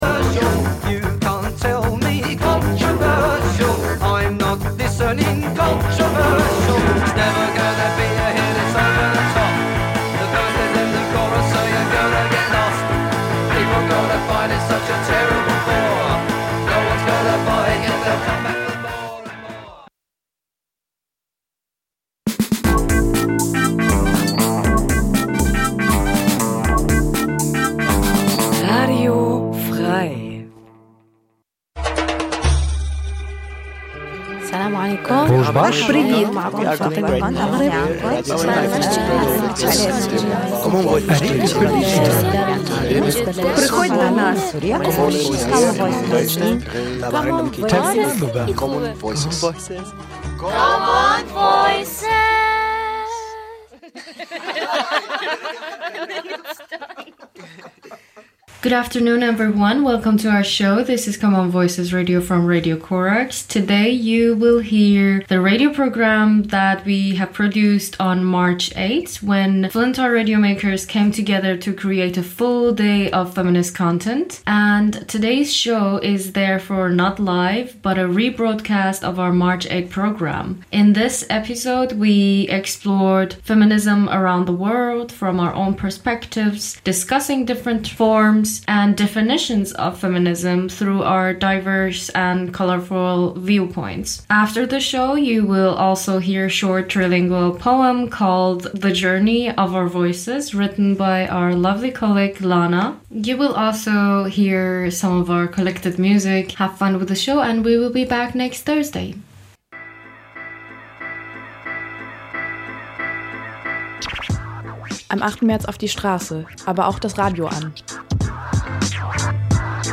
In der Sendung werden Neuerscheinungen aus den Bereichen Techno, House und Drum'n'Bass vorgestellt. Weiterhin informieren wir die H�rerschaft �ber stattfindende Parties am Wochenende, inklusive Kartenverlosung.